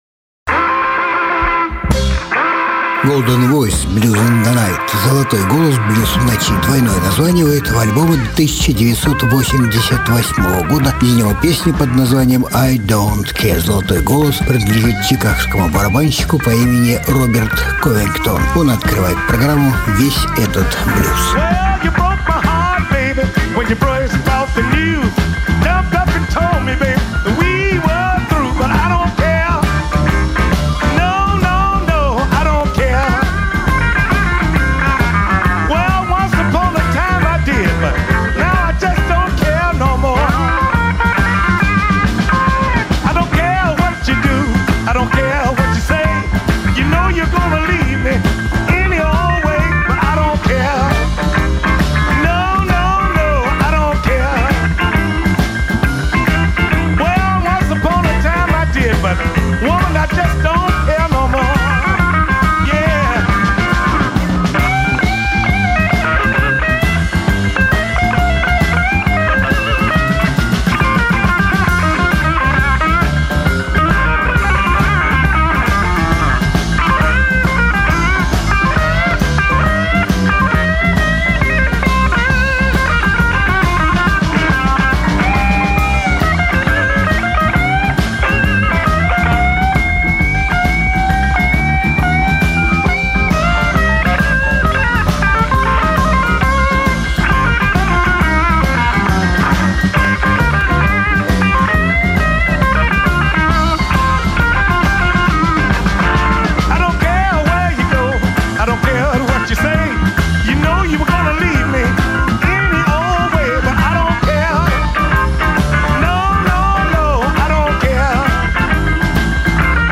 Жанр: Блюзы и блюзики